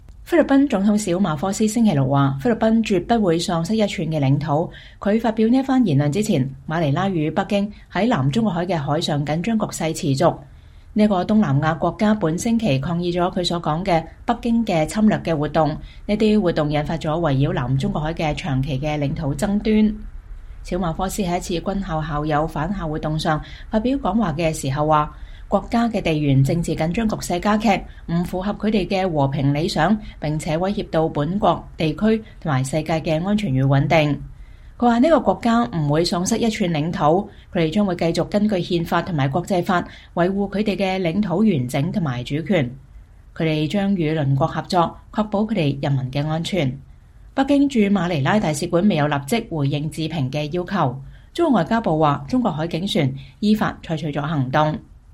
2023年1月18日星期三，在瑞士達沃斯舉行的世界經濟論壇上，菲律賓總統費迪南德·小馬科斯參加了一場對談。